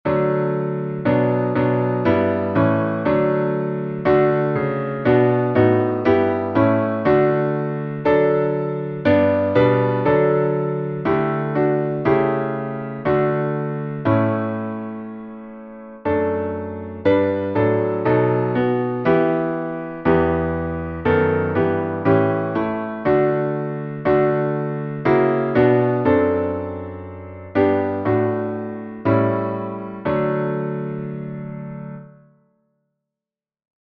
Melodia tradicional silesiana, 1842
salmo_143B_instrumental.mp3